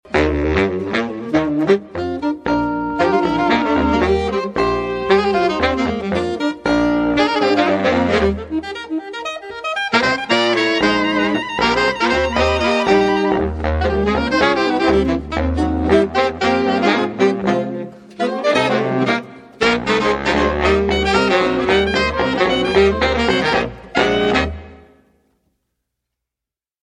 Cascade X-15 stereo ribbon